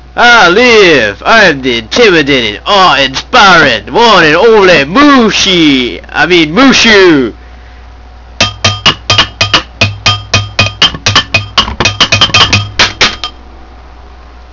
Then the cool music sounds come on.